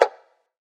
DDW4 PERC 4.wav